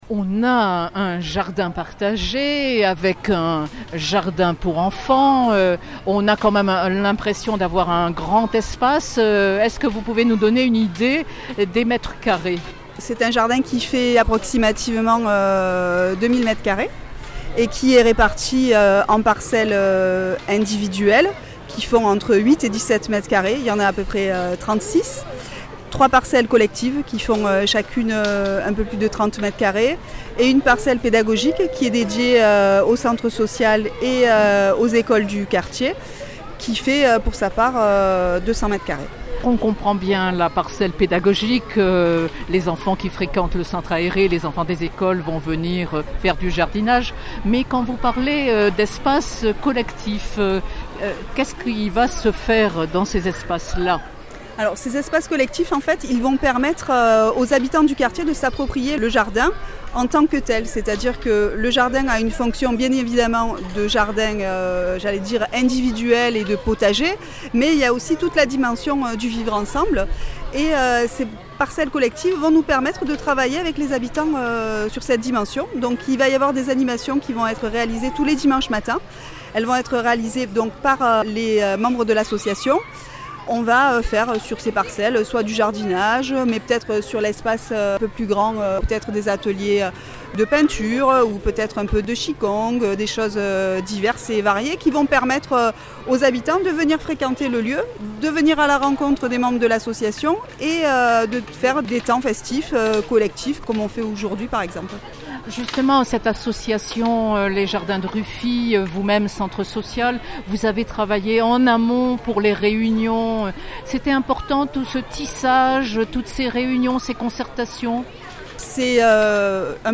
Au 14, rue de Ruffi dans le 3e arrondissement de Marseille, entre plants de fraises et barbe à papa, les habitants du quartier se pressaient pour cette fête de quartier organisée pour l’inauguration des Jardins de Ruffi à la fois jardin d’enfant et jardins partagés.